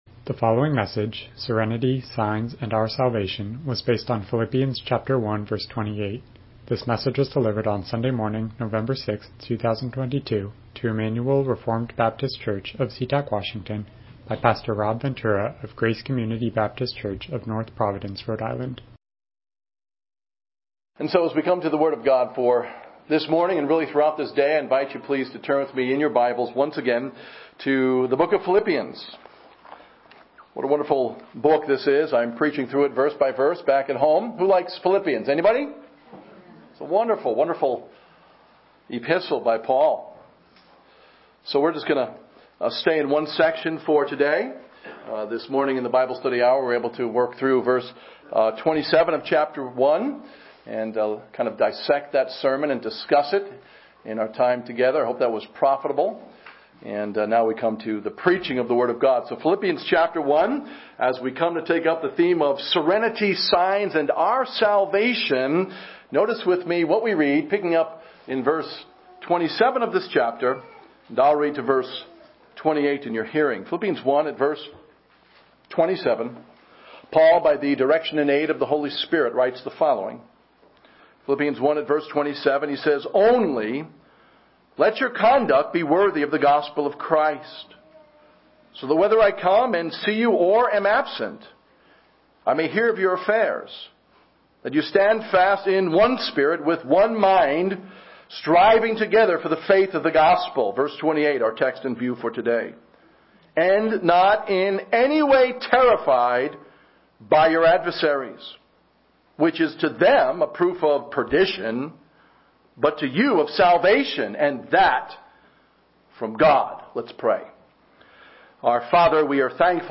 Passage: Philippians 1:28 Service Type: Morning Worship